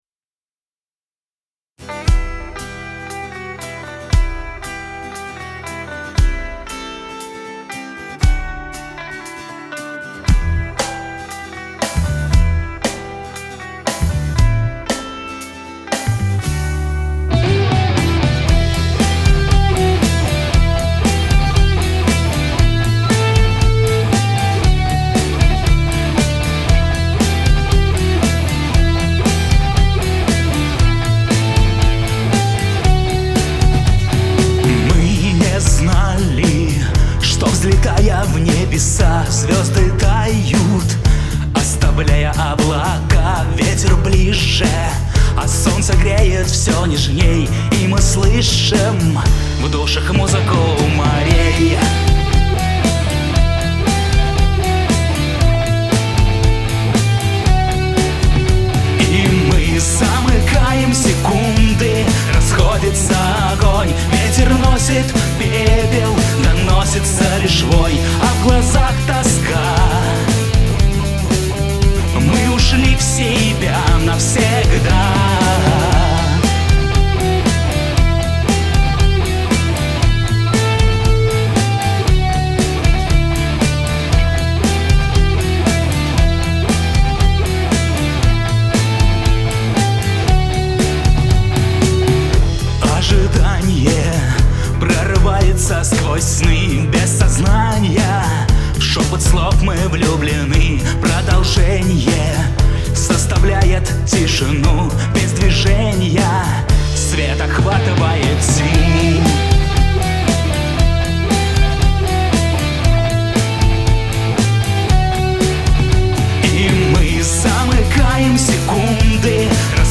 псковская рок-группа